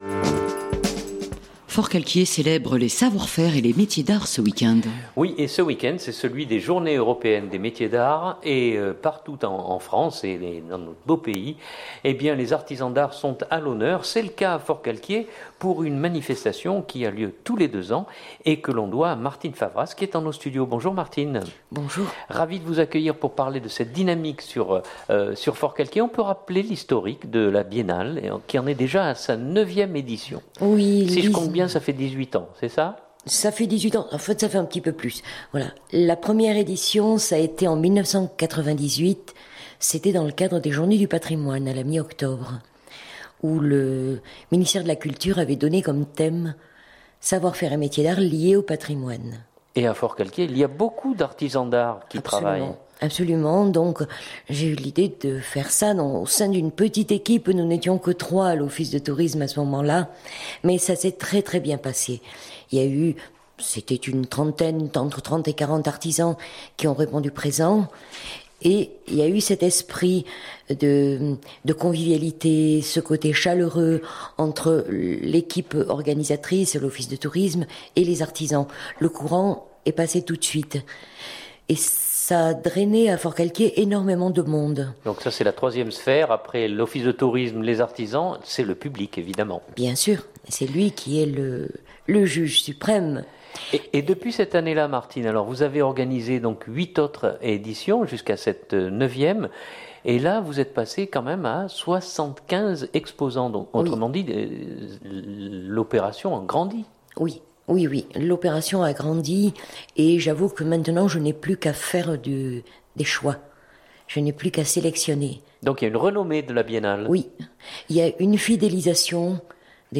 Sur le plateau de Fréquence Mistral